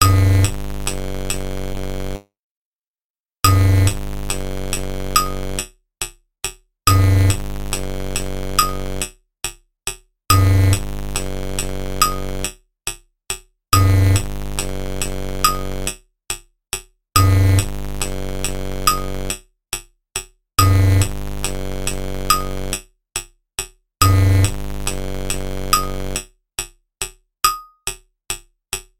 tikaiushie-chasy_24633.mp3